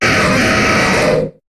Cri de Galeking dans Pokémon HOME.